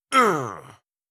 10. Damage Grunt (Male).wav